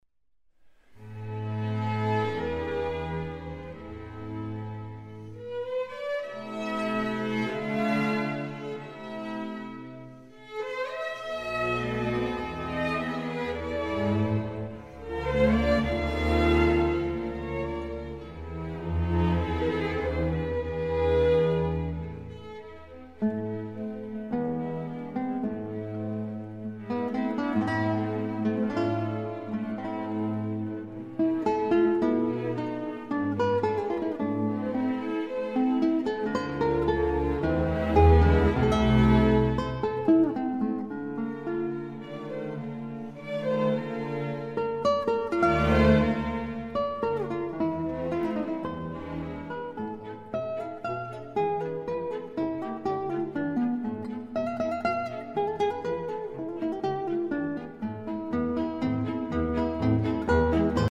Guitarist